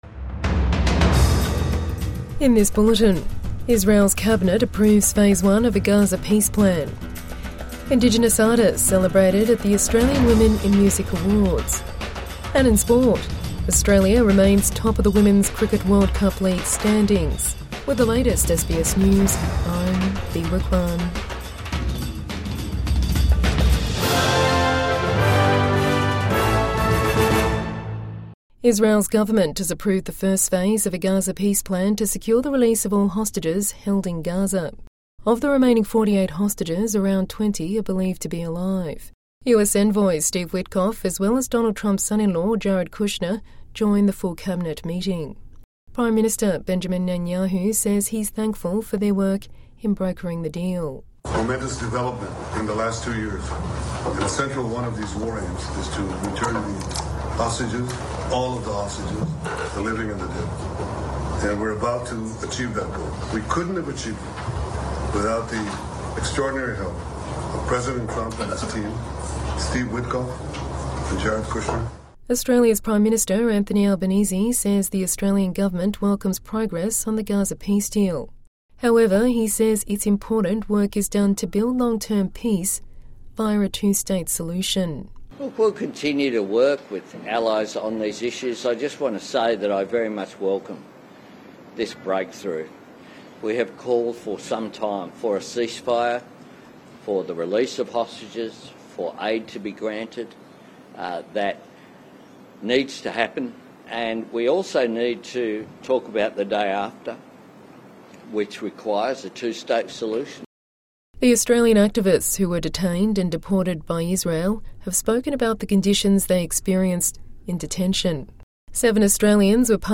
Australia welcomes Gaza peace plan, urges two-state solution | Midday News Bulletin 10 October 2025